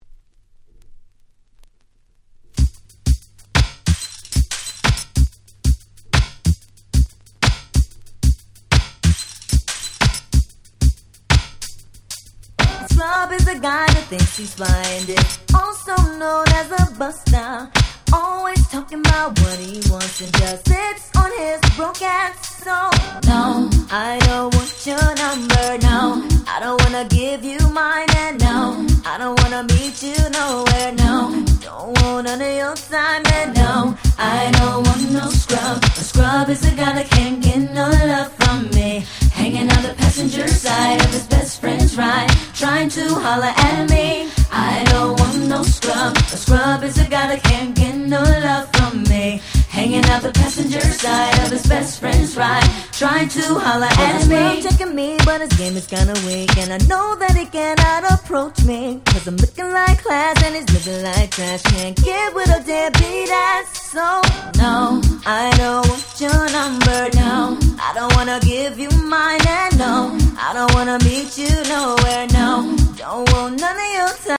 当時HotだったR&B4曲をこの盤オンリーのNice Remixに！！